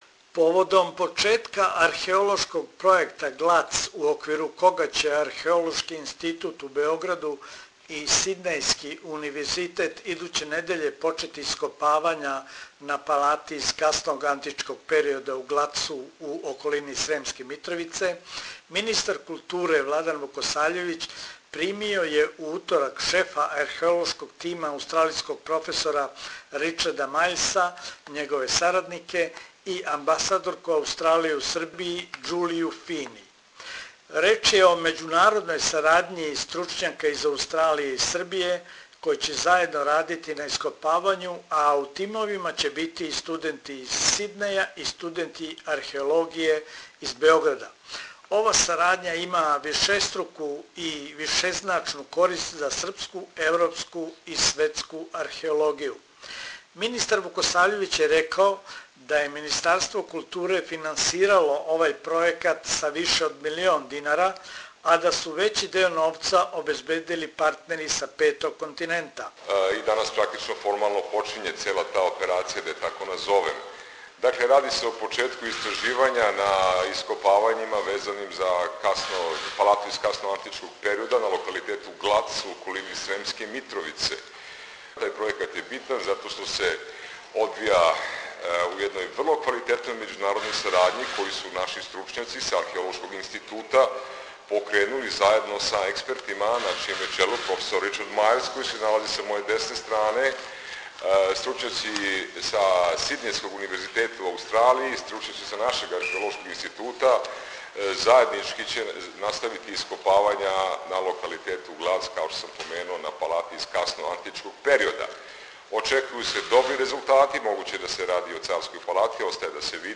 reports from the official launch of the Australia-Serbia archeological project Pannonia